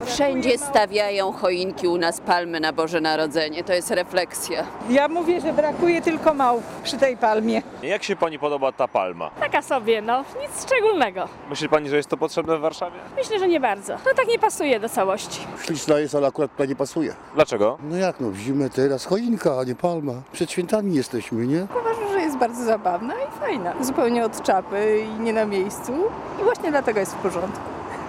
Posłuchaj co na to warszawiacy